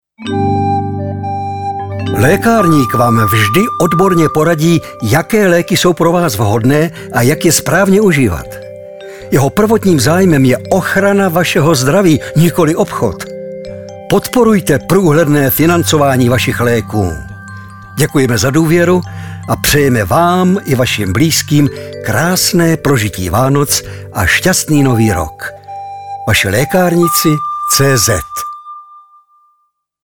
Hlasem Vašich lékárníků je i nadále charismatický herec Ladislav Frej.